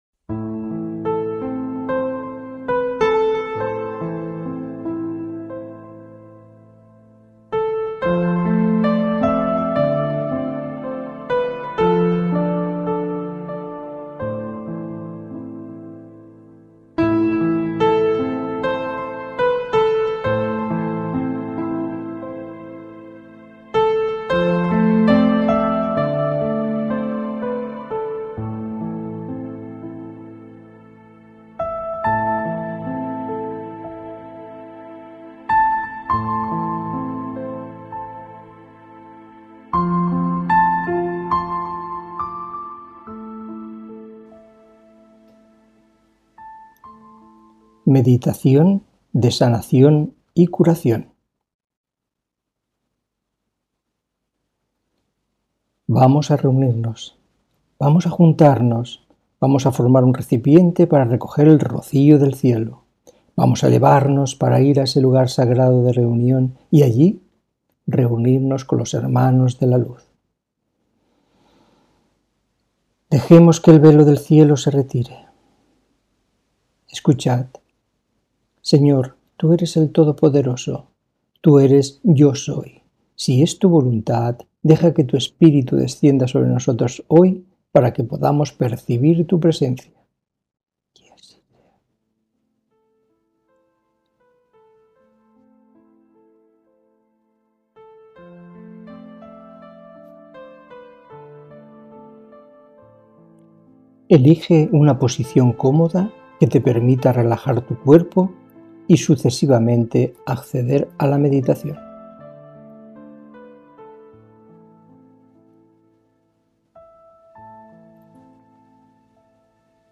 Meditación de Sanación y Curación